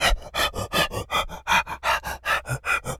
wolf_breathing_01.wav